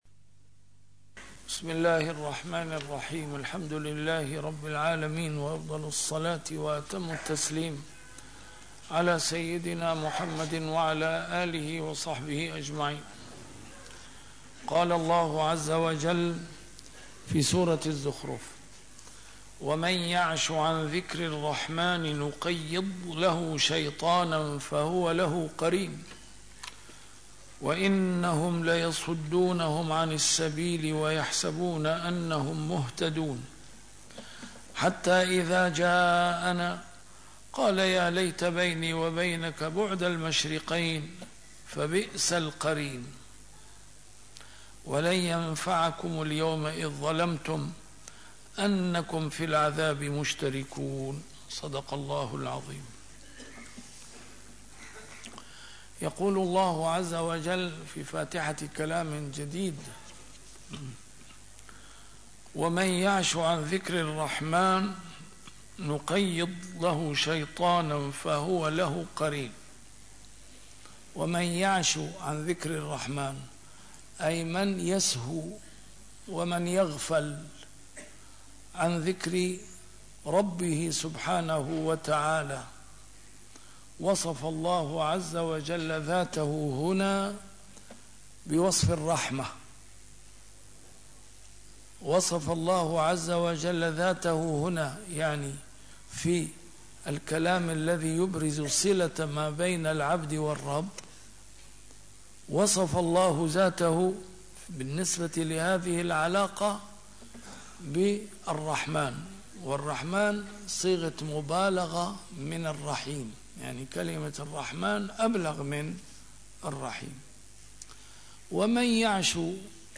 A MARTYR SCHOLAR: IMAM MUHAMMAD SAEED RAMADAN AL-BOUTI - الدروس العلمية - تفسير القرآن الكريم - تسجيل قديم - الدرس 582: الزخرف 36-39